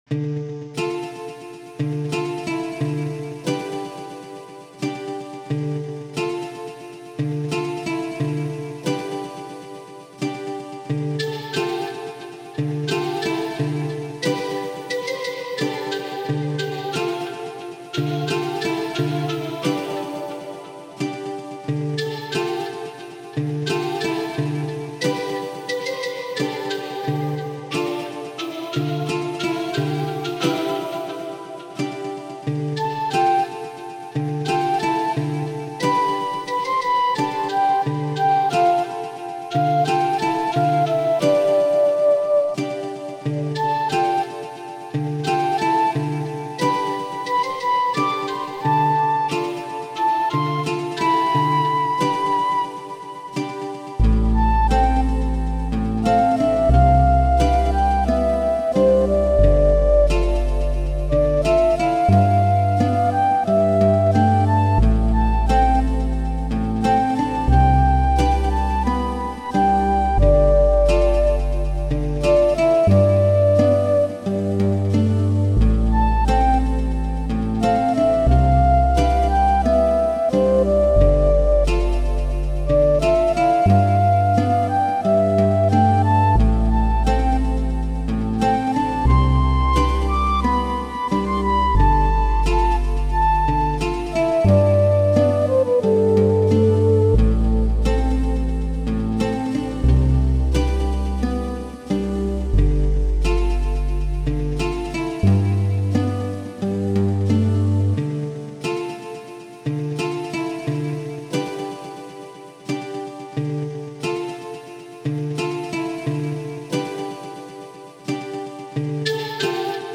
Peaceful RPG-esc
Man the melodies are sooo pretty! I love this so much, it's very medieval sounding.